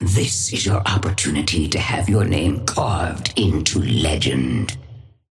Sapphire Flame voice line - This is your opportunity to have your name carved into legend.
Patron_female_ally_synth_start_04.mp3